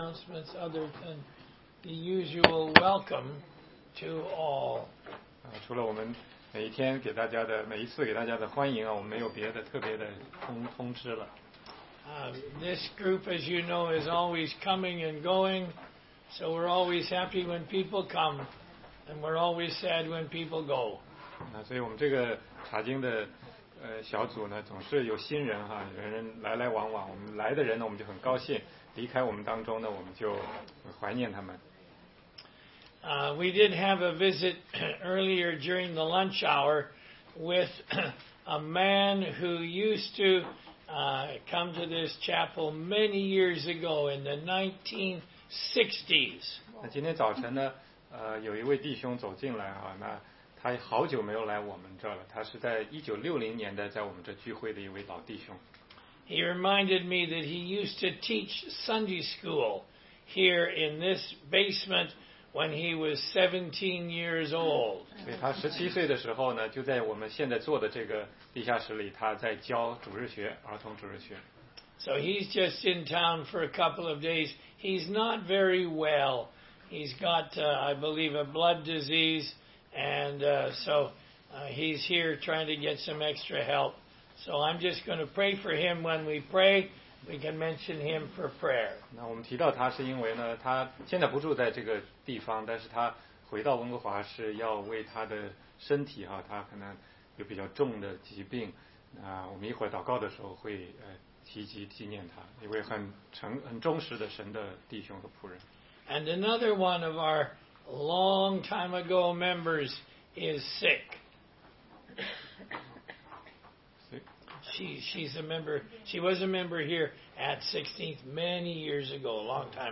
16街讲道录音 - 罗马书10章18节-11章21节：恩典若是在乎行为就不是恩典了